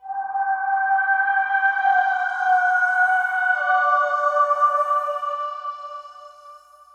synth04.wav